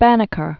(bănĭ-kər), Benjamin 1731-1806.